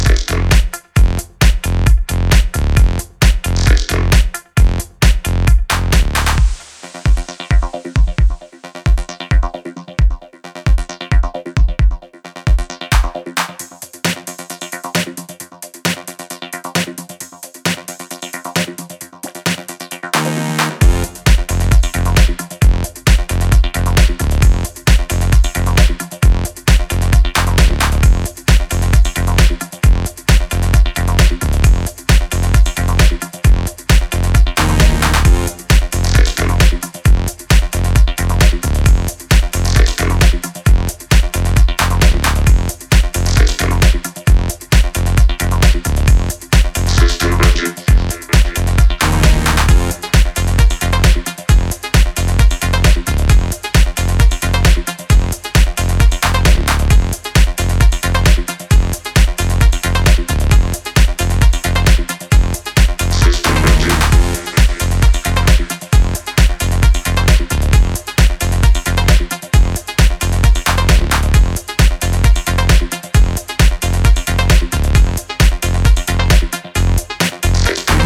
who hands out 3 track in perfect Electro House style.